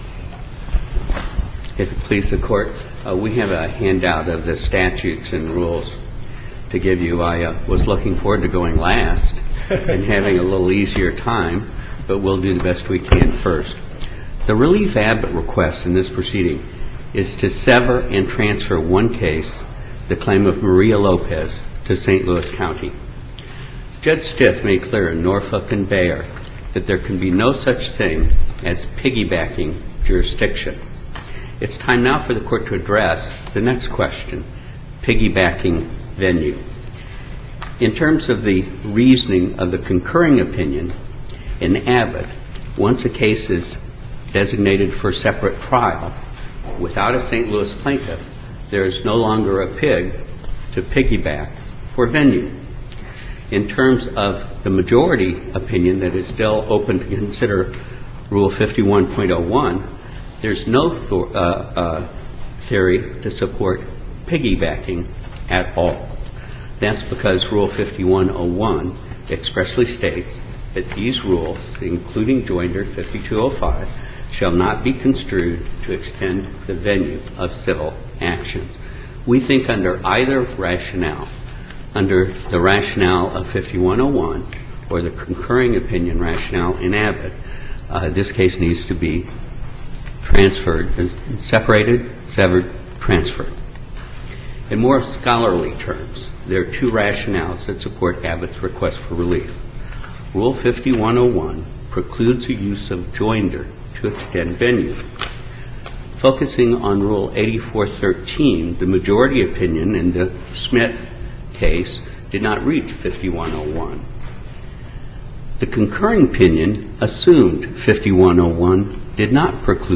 MP3 audio file of arguments in SC96718